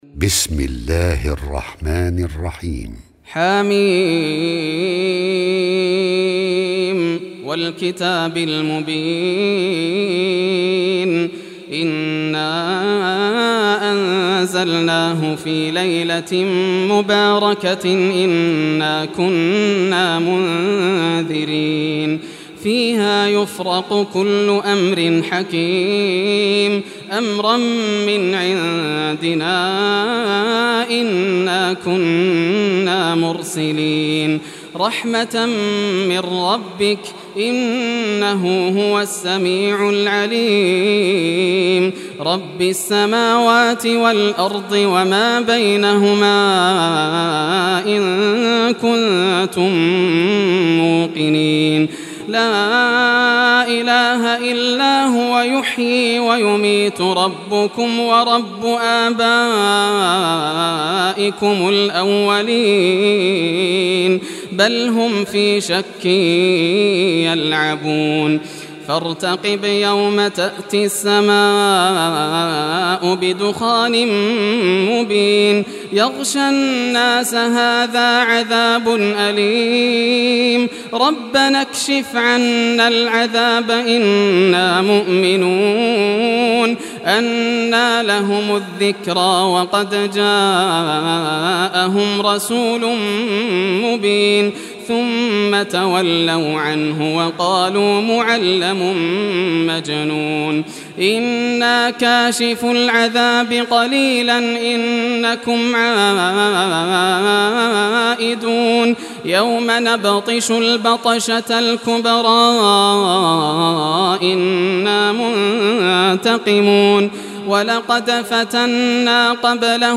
Surah Ad-Dukhan Recitation by Yasser al Dosari
Surah Ad-Dukhan, listen or play online mp3 tilawat / recitation in Arabic in the beautiful voice of Sheikh Yasser al Dosari.